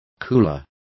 Complete with pronunciation of the translation of coolers.